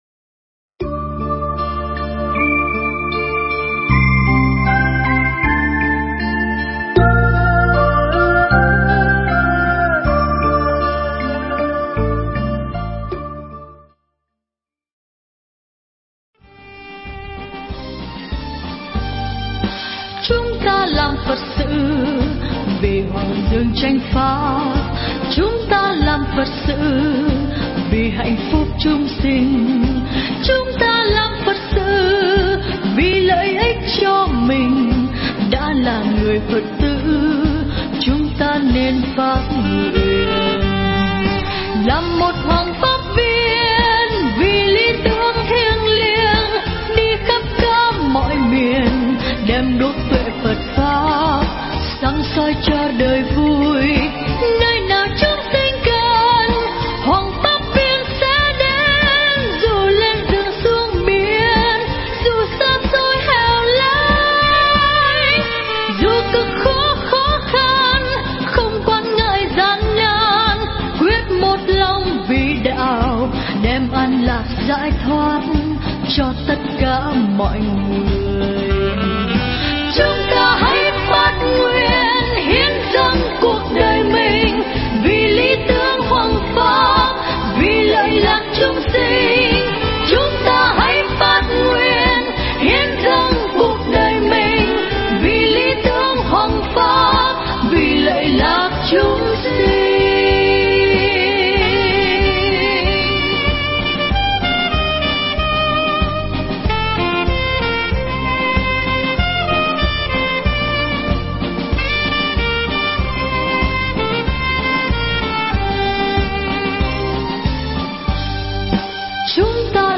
Nghe Mp3 thuyết pháp Nơi Kết Nối Tin Yêu
Mp3 pháp thoại Nơi Kết Nối Tin Yêu